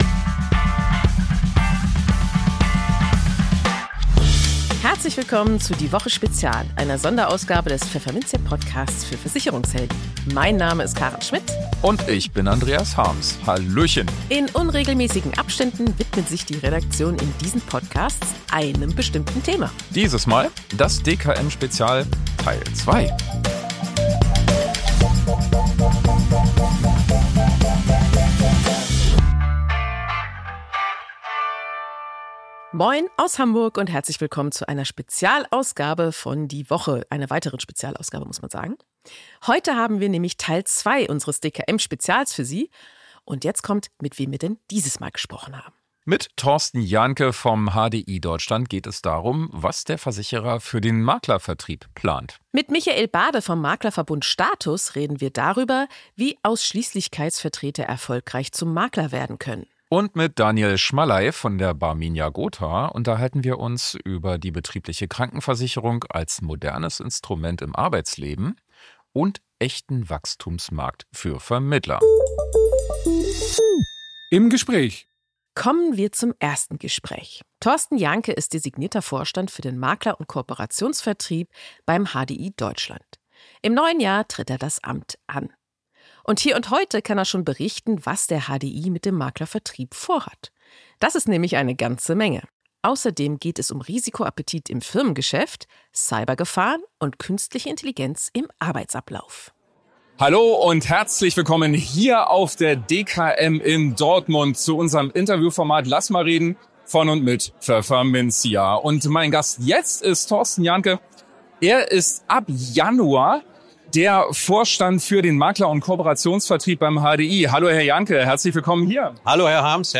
Beschreibung vor 5 Monaten Im zweiten Teil unseres DKM-Spezial-Sonderpodcasts haben wir drei Interviews für Sie, die wir auf der Messe DKM führten.